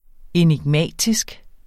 Udtale [ enigˈmæˀtisg ]